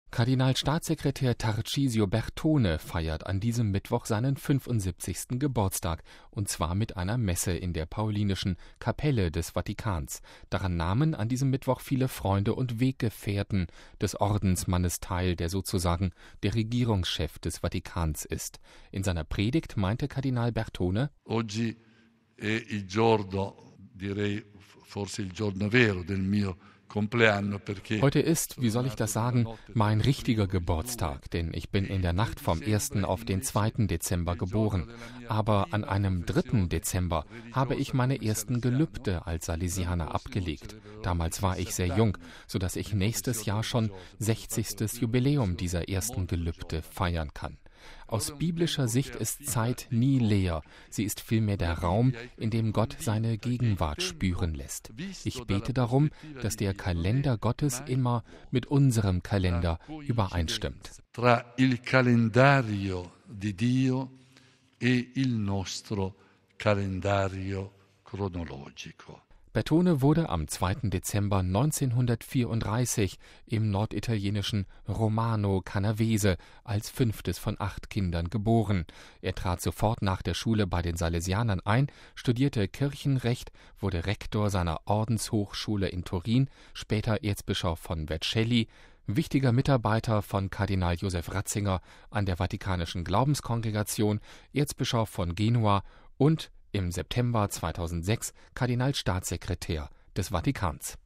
MP3 Kardinalstaatssekretär Tarcisio Bertone feiert an diesem Mittwoch seinen 75. Geburtstag – und zwar mit einer Messe in der Paulinischen Kapelle des Vatikans.
In seiner Predigt meinte Bertone: